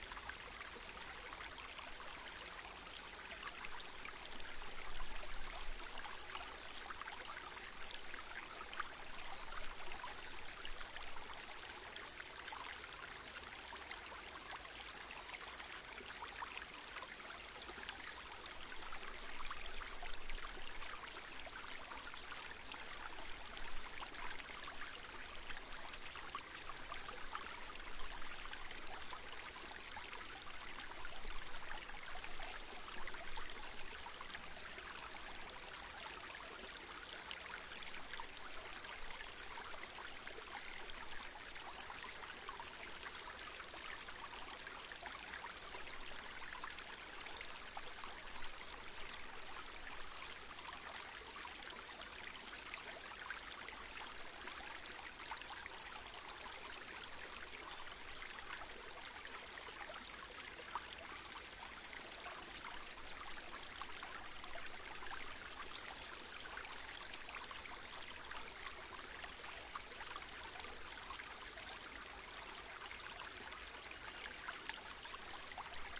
描述：涓涓细流的立体声可循环录音。用一对crown "soundgrabber II's"贴在纸箱的两侧进行录音，以获得类似于人头的立体声成像。轧掉了附近汽车交通的低频隆隆声。
标签： 小溪 环路 环境
声道立体声